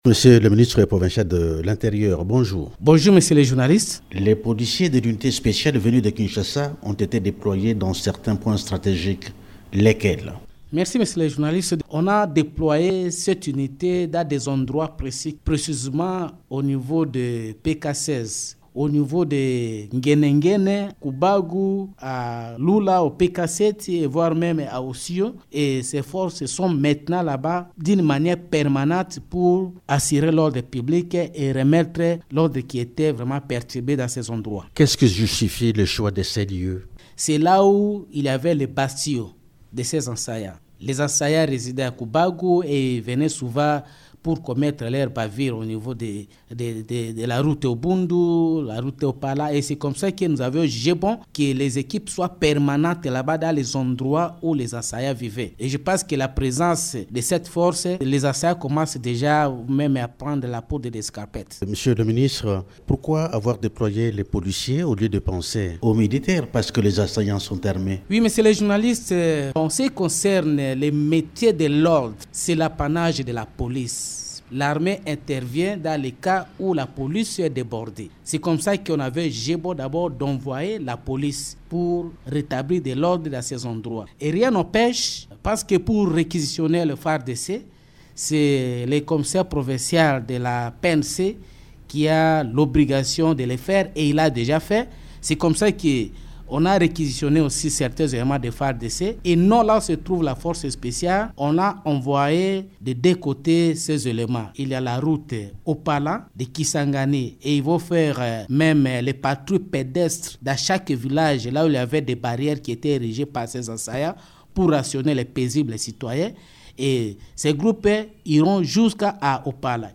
Invité de Radio Okapi, le ministère provincial de l’Intérieur de la Tshopo, Jean-Norbert Lolula soutient que ce conflit communautaire serait entretenu par des personnes venues d’ailleurs.